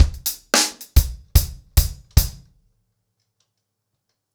DaveAndMe-110BPM.11.wav